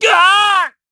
Siegfried-Vox_Damage_kr_03.wav